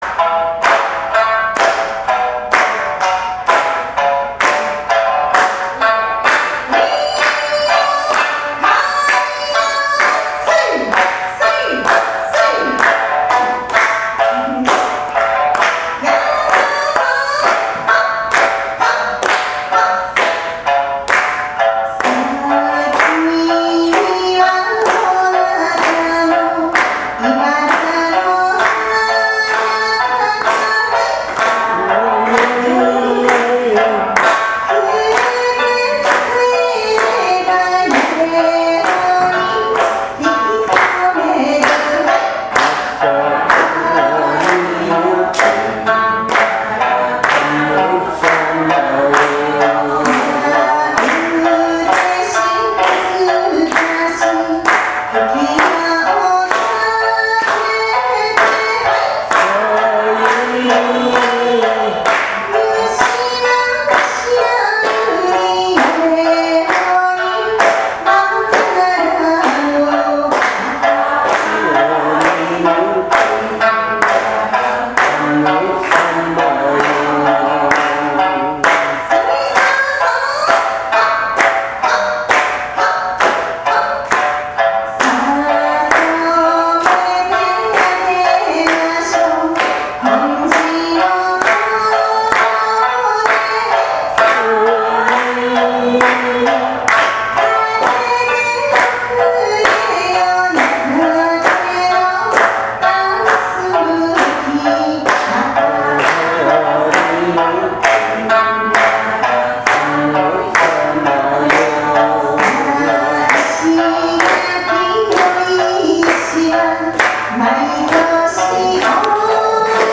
・　石垣島の民謡「安里屋（あさどや）ユンタ」をアップしました。
◎　お姉さんが唄う「安里屋ユンタ」
夕食でお酒を飲みすぎて、せっかくの唄に雑音を入れてしまいました。ゴメンナサイ！